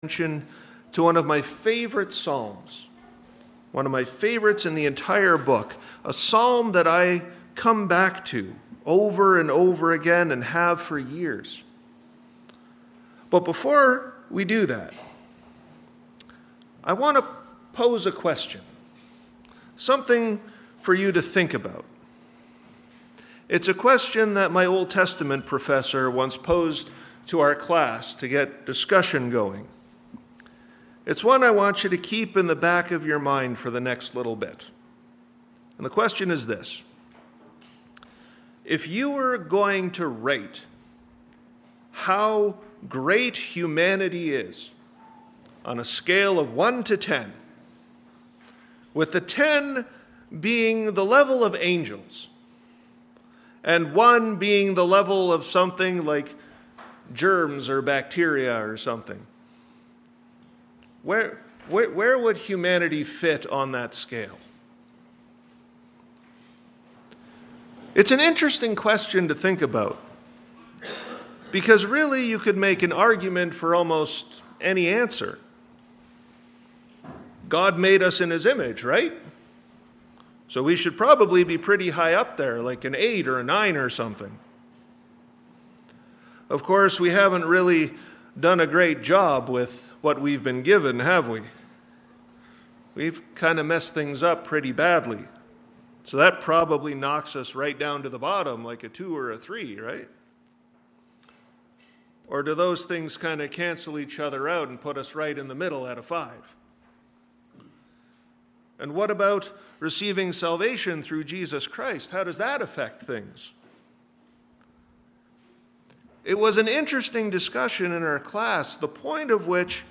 Posted in Sermons .